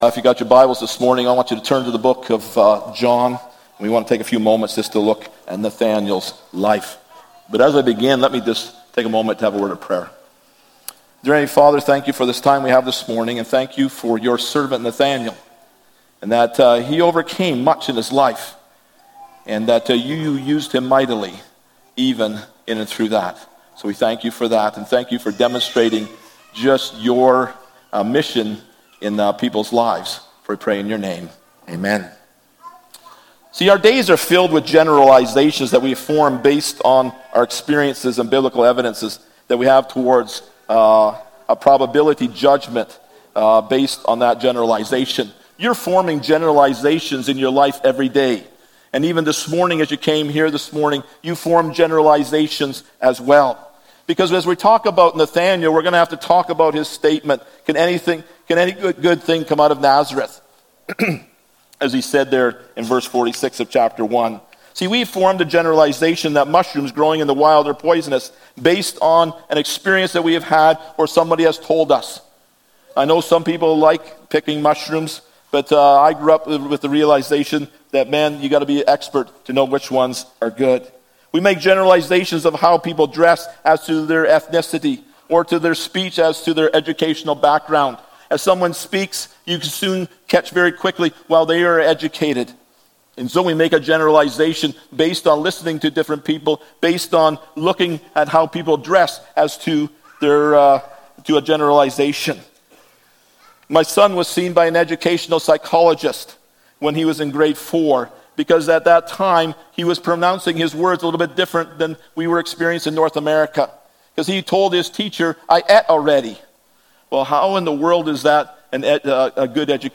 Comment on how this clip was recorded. John 1:45-51 Service Type: Sunday Morning « God Needs Tentmakers Deborah